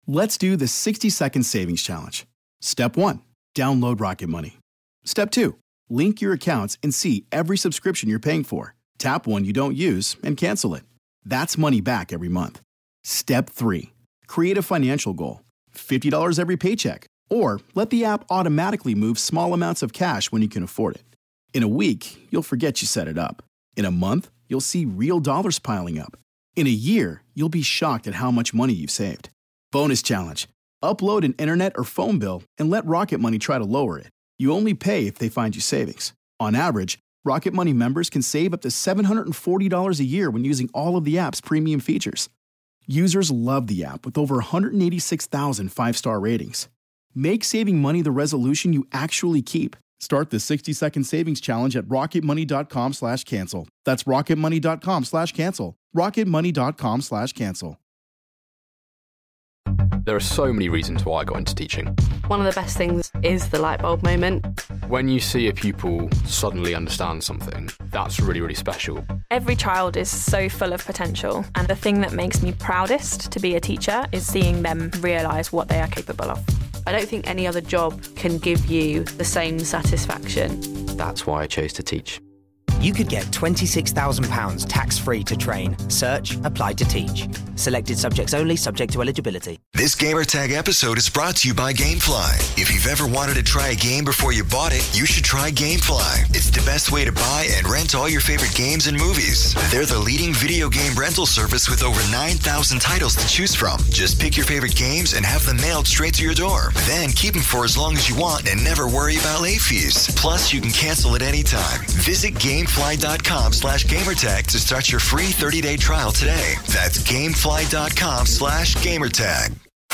Interview for Ubisoft's Hack & Slash game, For Honor.